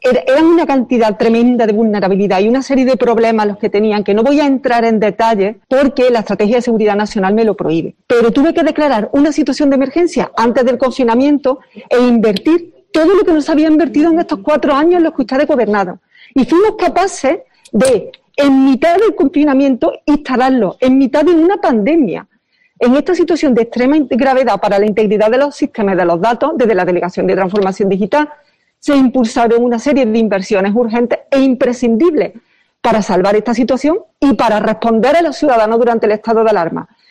Lourdes Morales ha comparecido en el pleno para dar cuenta de las actuaciones llevadas a cabo por su delegación